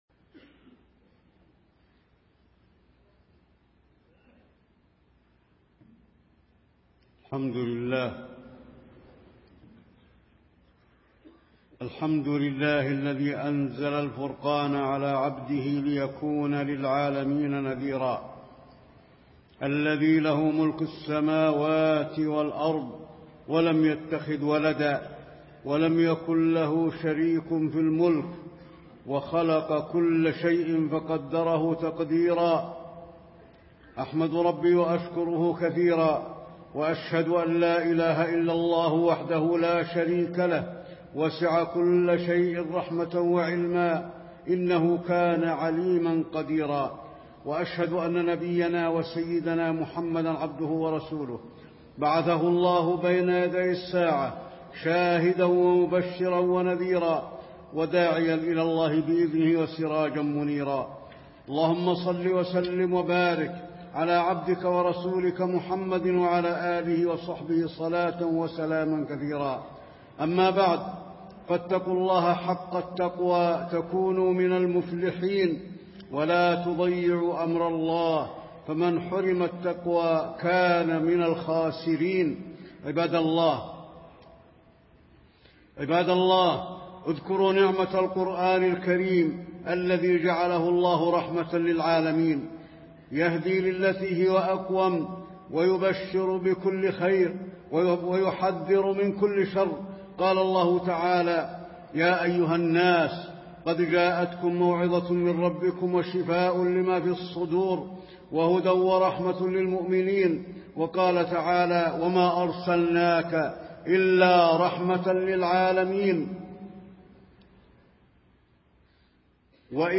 تاريخ النشر ٢٨ رجب ١٤٣٤ هـ المكان: المسجد النبوي الشيخ: فضيلة الشيخ د. علي بن عبدالرحمن الحذيفي فضيلة الشيخ د. علي بن عبدالرحمن الحذيفي عظمة القرآن الكريم